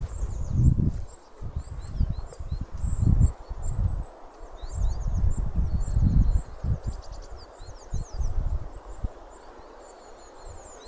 Putns (nenoteikts), Aves sp.
StatussDzirdēta balss, saucieni
Piezīmesierakstīts ejot, ar lielu varbūtību noteikts uz vietas, vēl ticamāks likās pēc nākošajā dienā ilgstoši klausītā putna Jūrmalciemā, putns nav vizuāli redzēts. Sugai atbilstoša uzvedība ejot gar krūmu vairāku saucienu sērija vēlāk arī saucot aizlidojot pāri ceļam uz ZA (vismaz 30m lidojums).